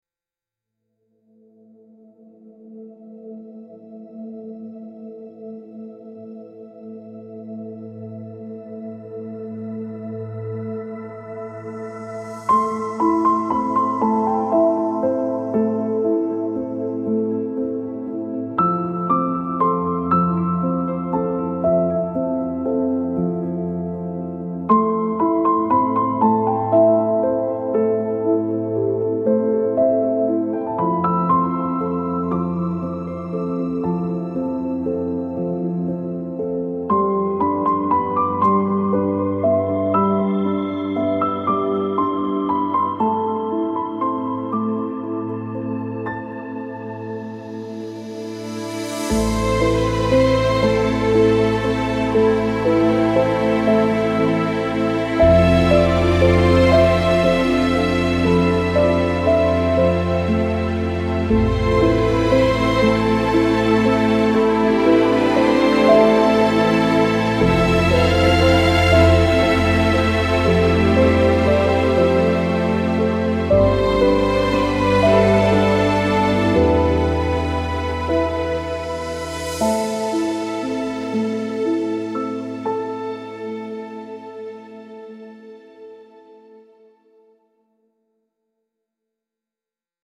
dreamy ethereal pads with soft piano keys and tender violin melody